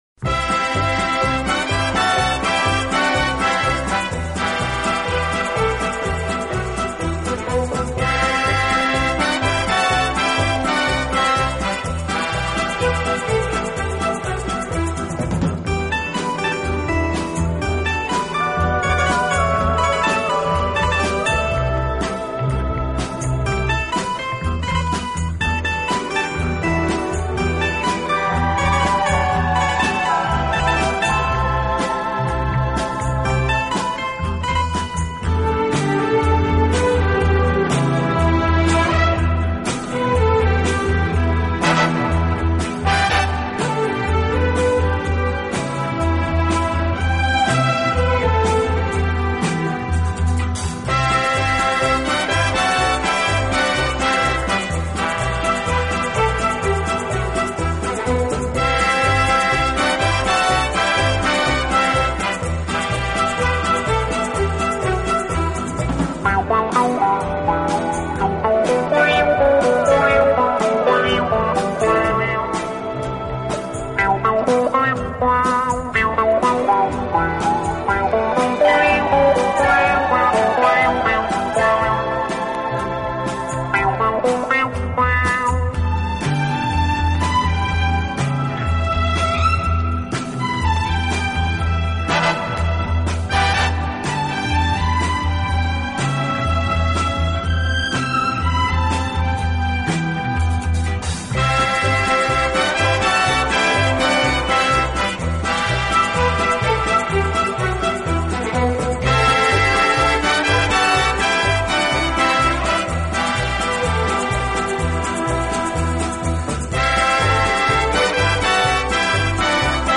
【顶级轻音乐】
其风格清新明朗，华丽纯朴，从不过分夸张。